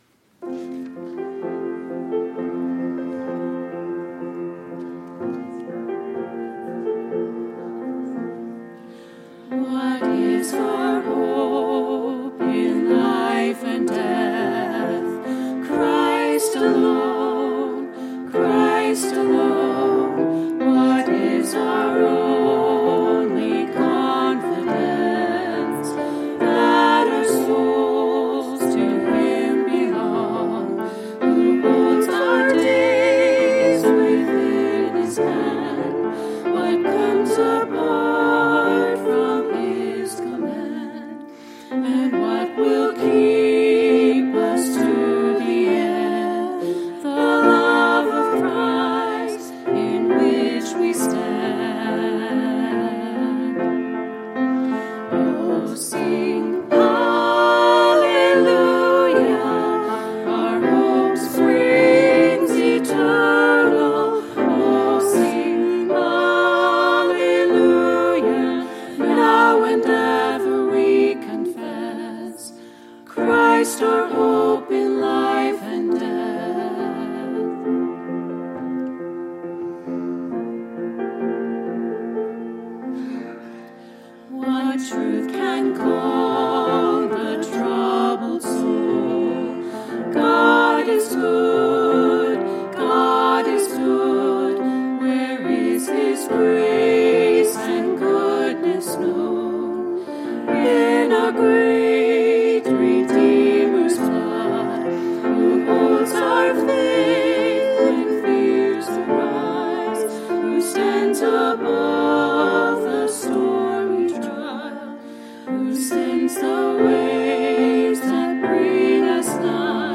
Ladies Trio